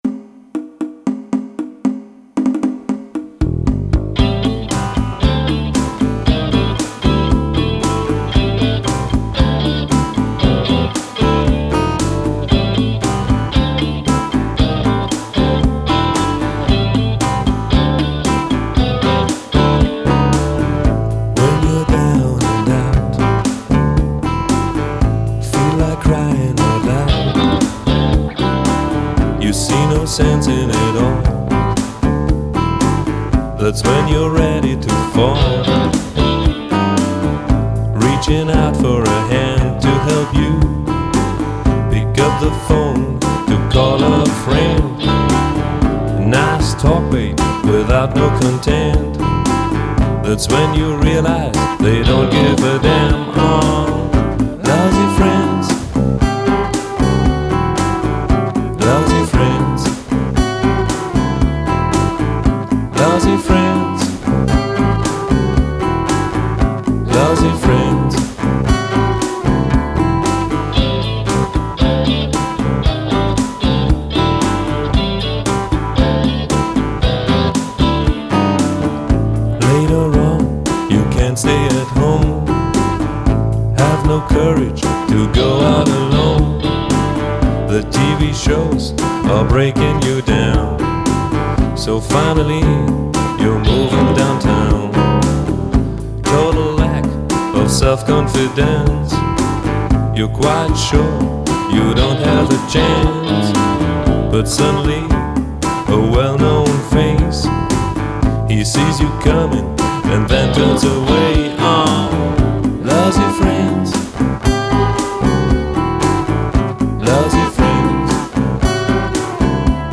Rhythm Guitar
Guitar Solo
All Other Instruments&Programming: